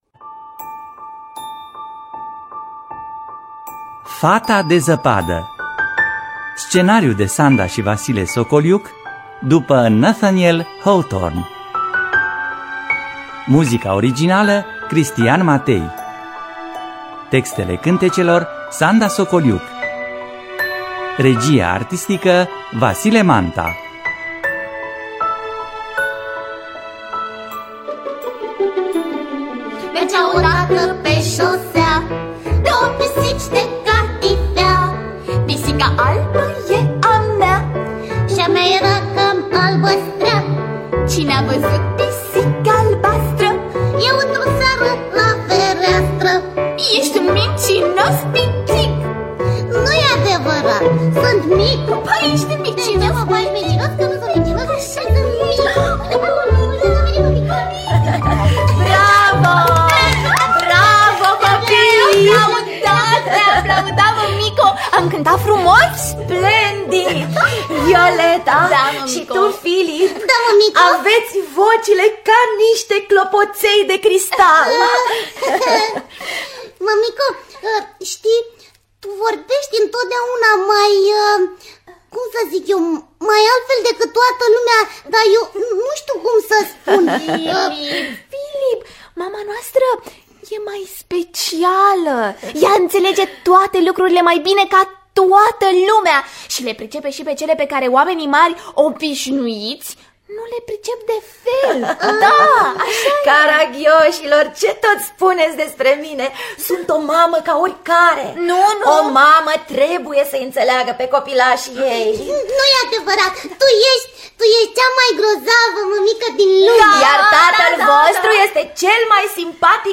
Fetiţa de zăpadă de Nathaniel Hawthorne – Teatru Radiofonic Online